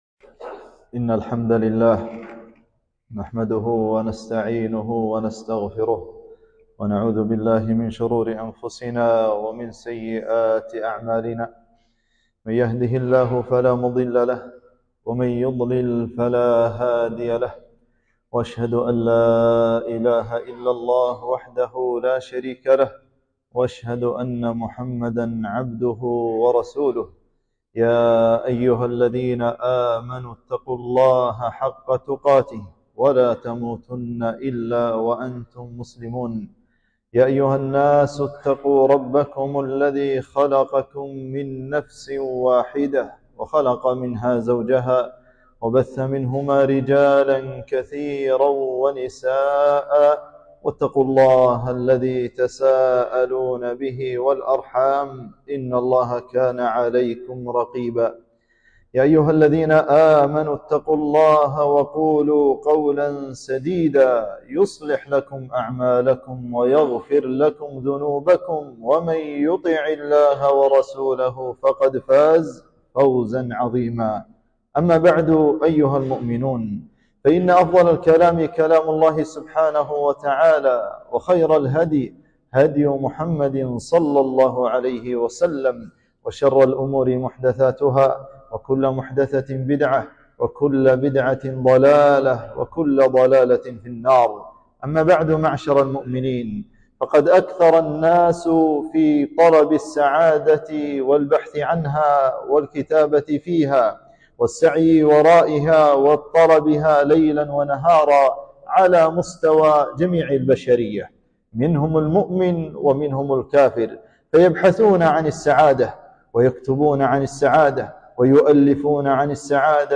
خطبة - تحقيق السعادة